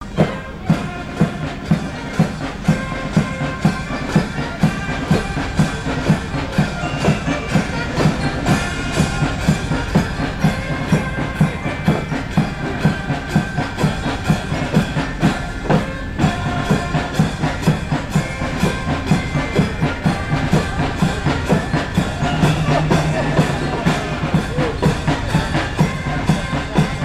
Fanfare
Île-d'Yeu (L')
chansons à danser